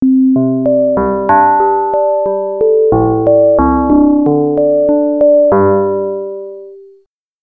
The result is usually a very distorted tone, but the sound is very distinct and can be used as a wonderful effect.
EXAMPLE 1: A sine wave with a long release envelope (SR2) and Pitch Modulation enabled:
c700_pmod_sines.mp3